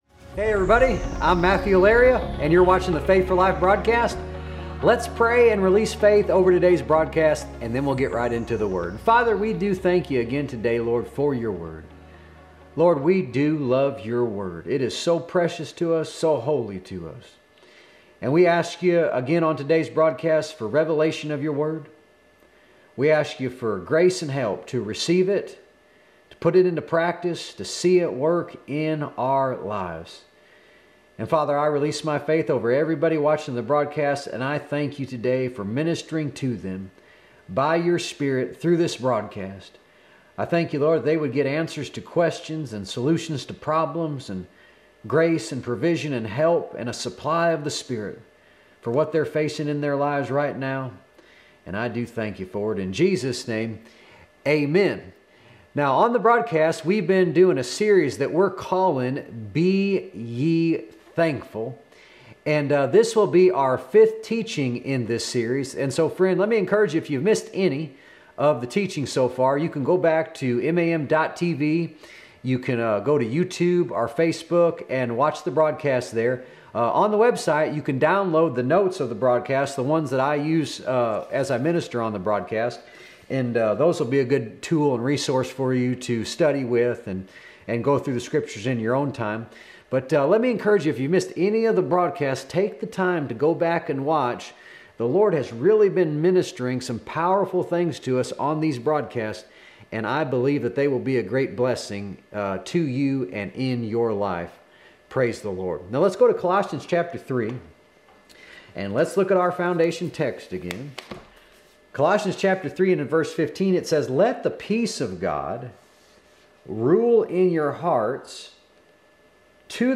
A message from the series "Be Ye Thankful."